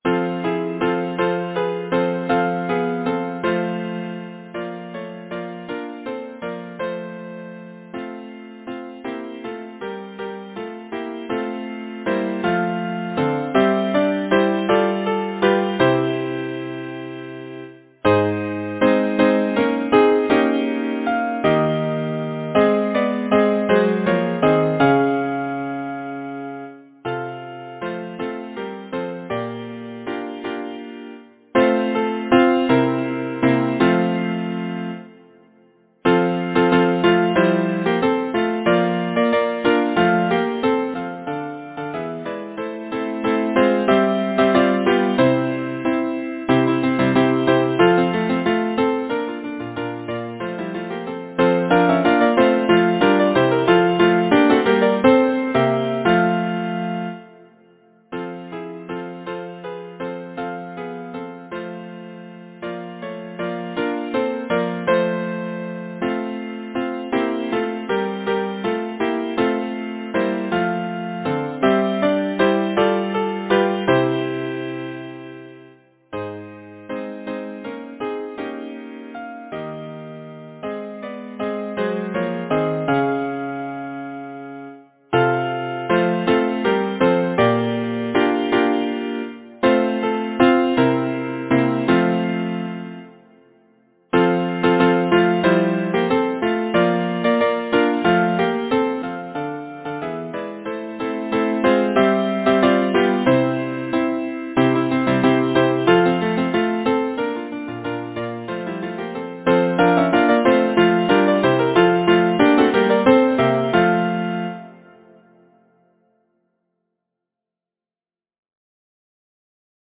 Title: O’er Silv’ry Waters Composer: Edward Bunnett Lyricist: Number of voices: 4vv Voicing: SATB Genre: Secular, Partsong
Language: English Instruments: A cappella